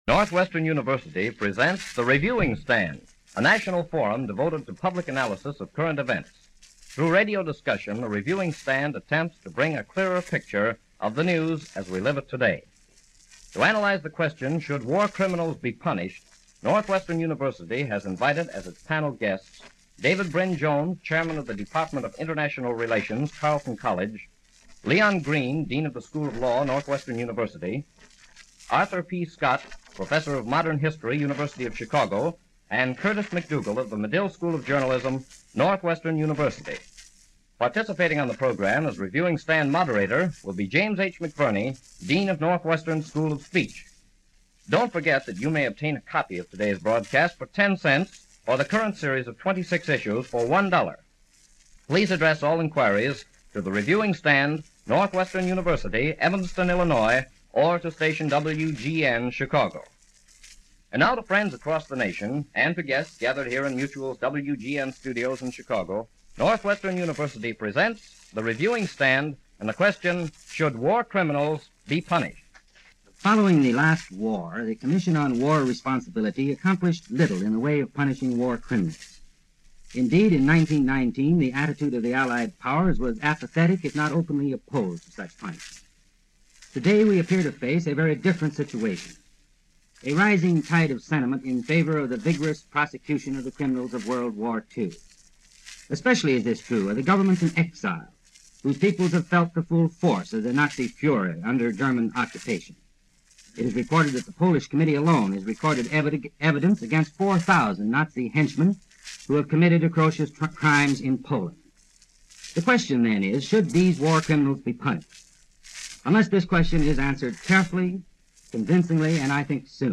The Matter Of War Crimes - 1943 - Past Daily After Hours Reference Room - Broadcast June 13, 1943 - Northwestern Reviewing Stand
The panel mulled over the question and divided it into three sections: 1. the crime of who started the conflict – 2. the act of atrocities in violation of the civilized conscience 3. a crime involving a violation of a particular article or convention recognized in International law.